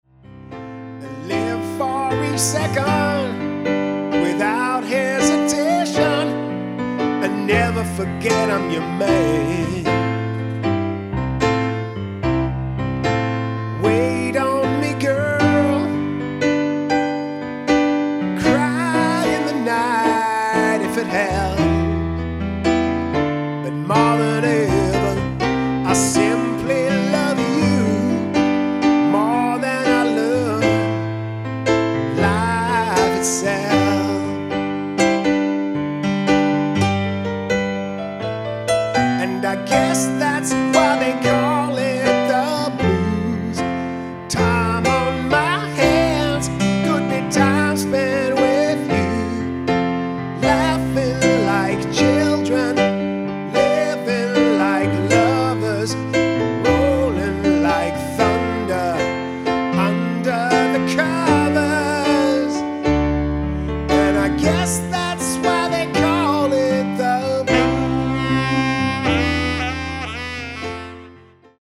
Piano, Saxophone, Vocals and Percussion
Keyboard, Sax, Vocal, Percussion Duo for hire